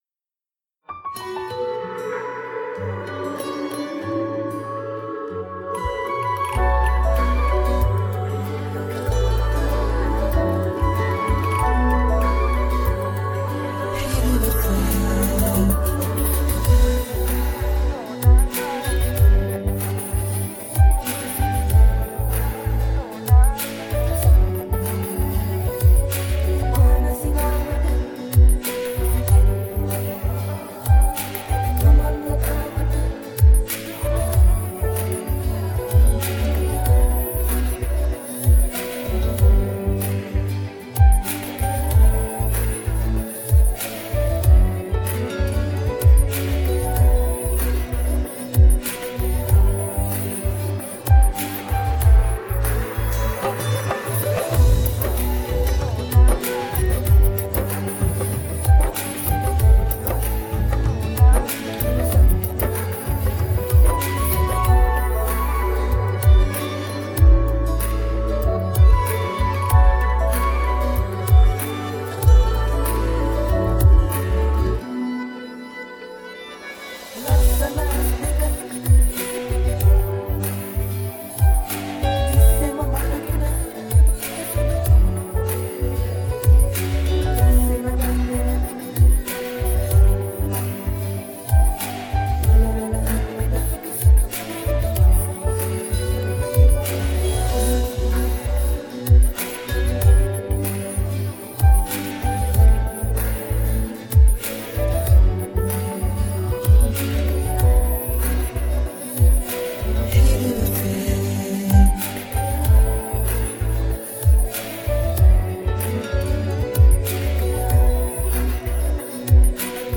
Sing with Us
Play Karaoke & Sing with Us